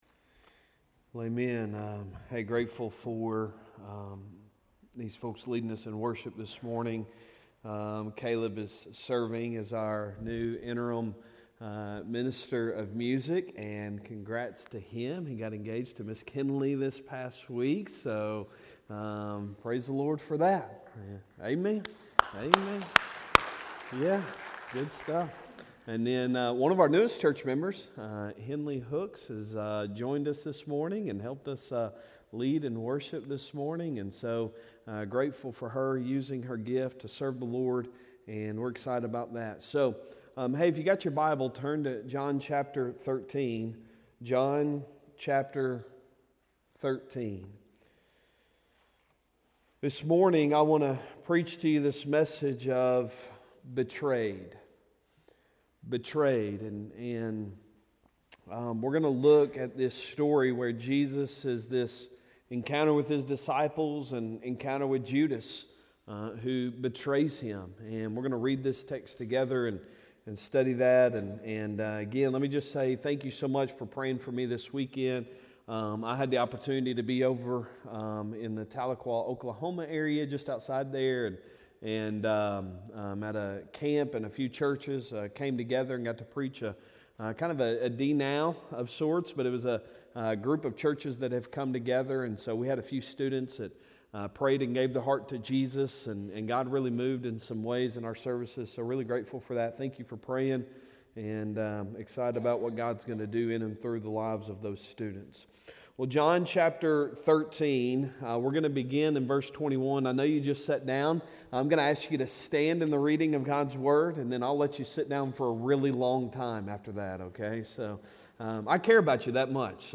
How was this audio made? Sermons , Sunday Mornings